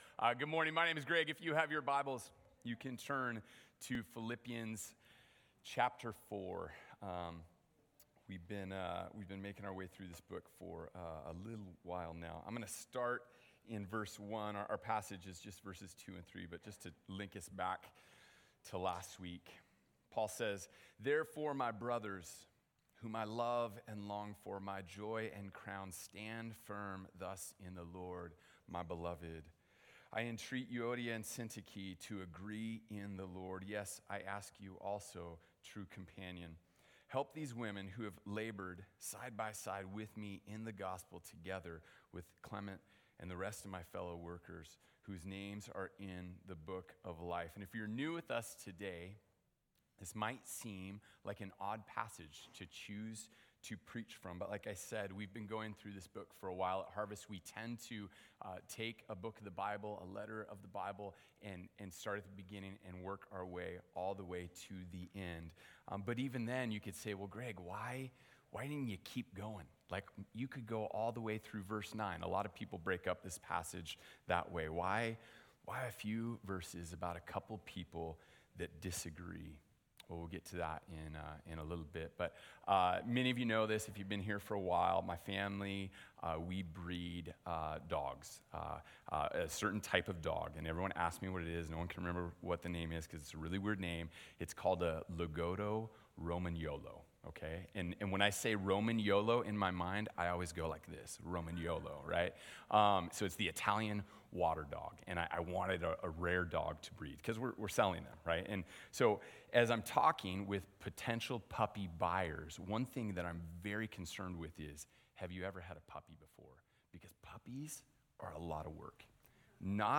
sermon_3_9_25.mp3